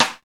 SNR XXSTIK0O.wav